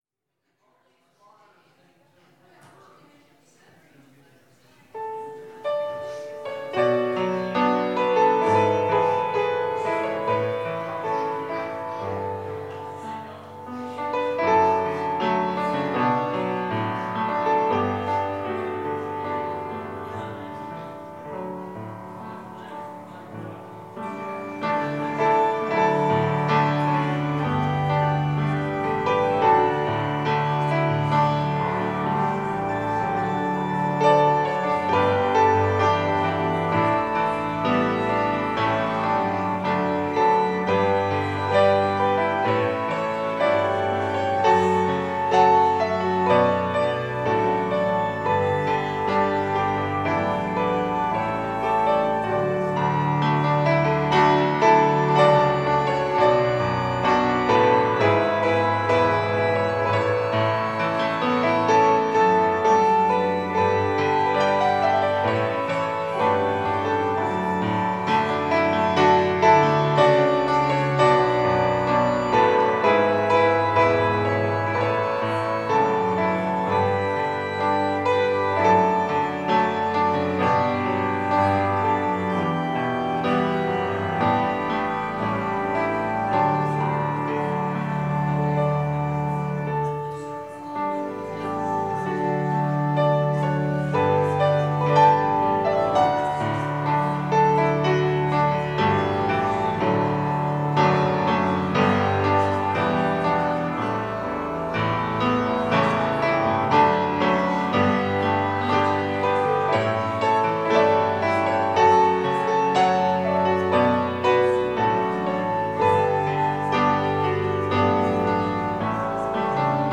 Series: Music